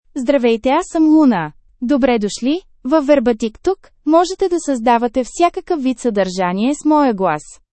LunaFemale Bulgarian AI voice
Luna is a female AI voice for Bulgarian (Bulgaria).
Voice sample
Listen to Luna's female Bulgarian voice.
Luna delivers clear pronunciation with authentic Bulgaria Bulgarian intonation, making your content sound professionally produced.